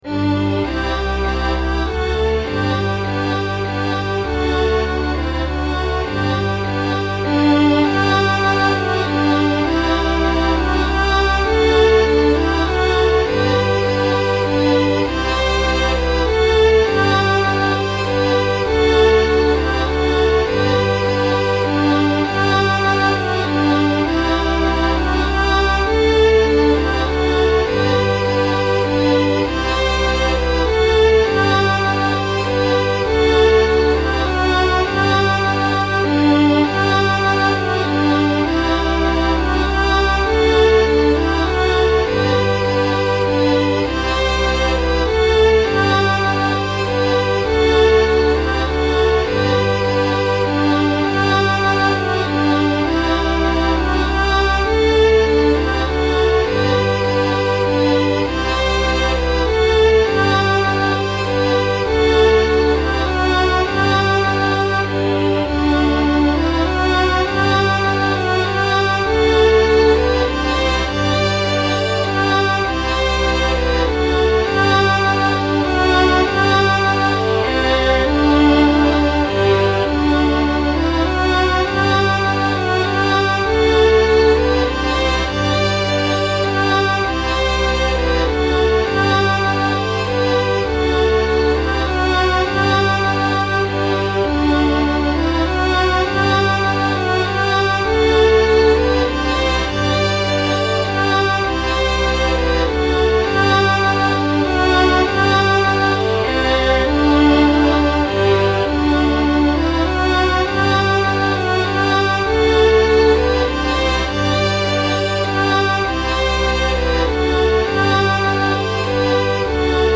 I scored piano accompaniment and split the right hand up between Violin 2 and Violin 3.  I used the left hand notes for the Contrabass part.
Heralding-His-Birth-Strings-Only.mp3